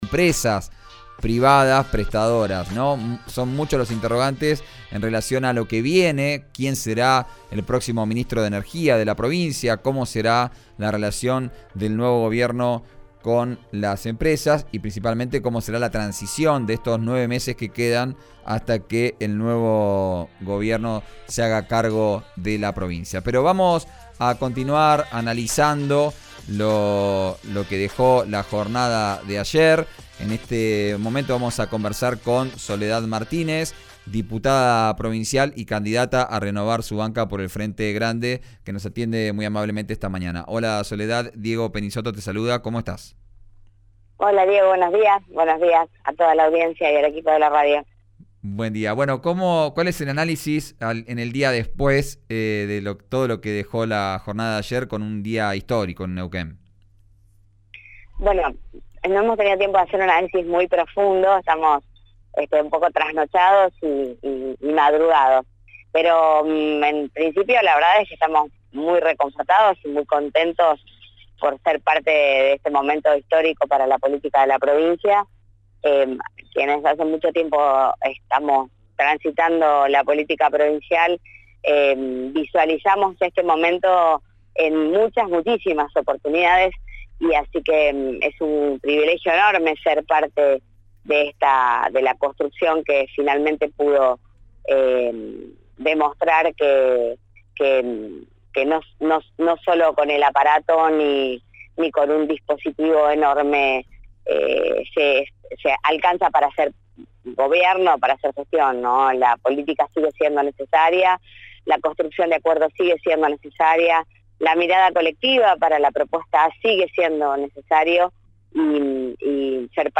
Escuchá a Soledad Martínez, diputada provincial y candidata a renovar su banca por el Frente Grande en Vos al Aire, por RÍO NEGRO RADIO: